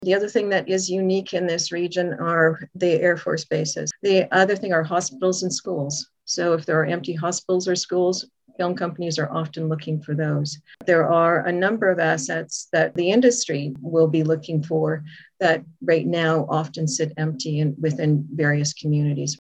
Belleville's Economic and Destination Development Committee holds a virtual meeting, on August 26, 2021.